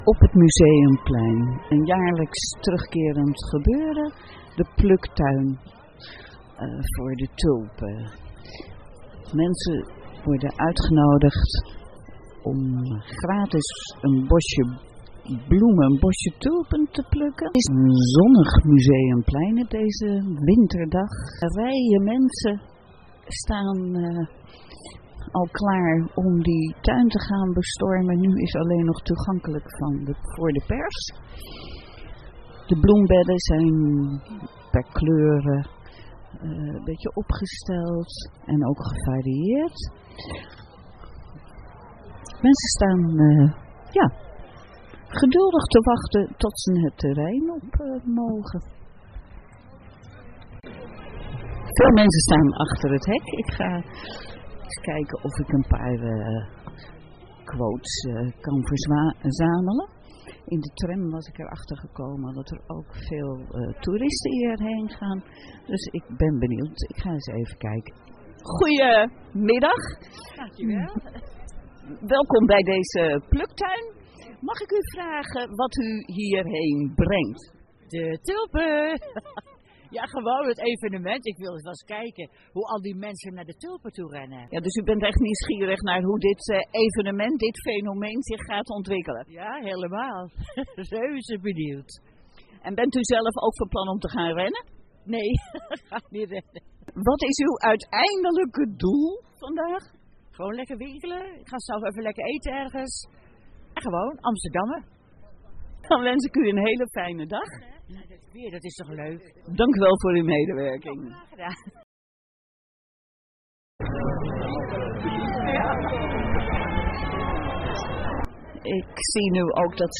Met deze podcast geef ik een kijkje in mijn leven als audiofotojournalist en audiostraatfotograaf.
Jaarlijks gehouden evenement Nationale Tulpendag. Het is de opening van het tulpenseizoen. Mensen kunnen gratis een bosje tulpen plukken. Sinds een paar jaar wordt het op het Museumplein gehouden.